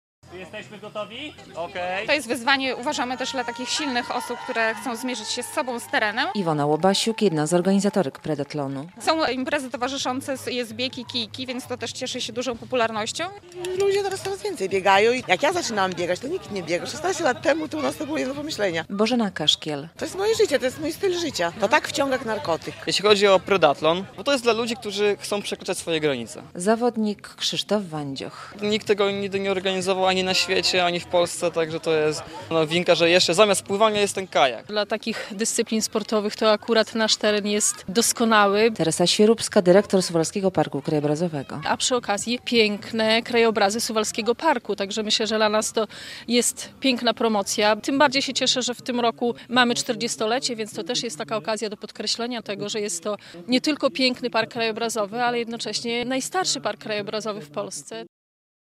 Predathlon na Suwalszczyźnie - relacja